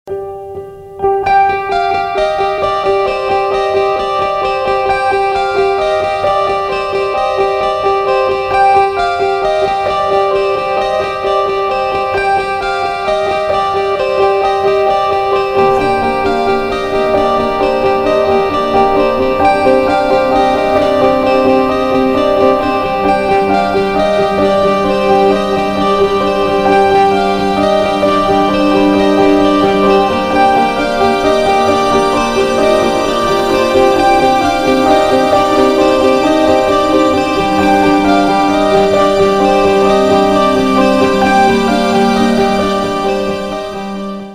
• Качество: 256, Stereo
без слов
OST